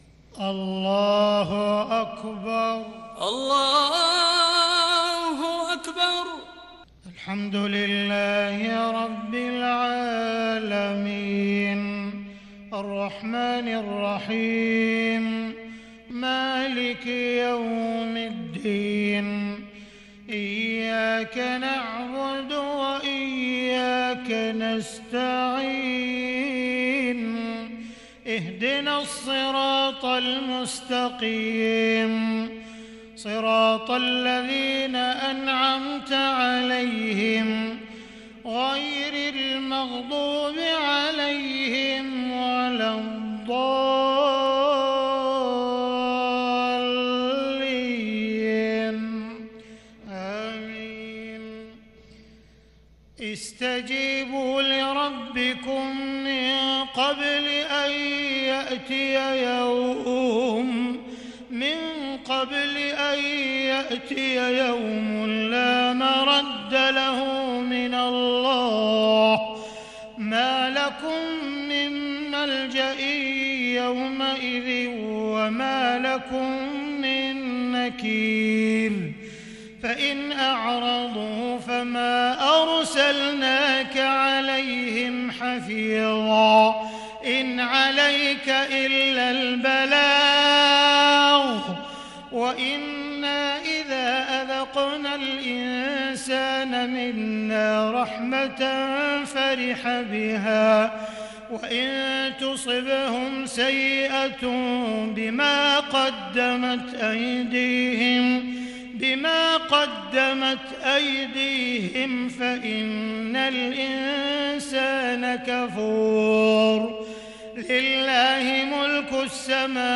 صلاة العشاء للشيخ عبدالرحمن السديس 19 صفر 1442 هـ
تِلَاوَات الْحَرَمَيْن .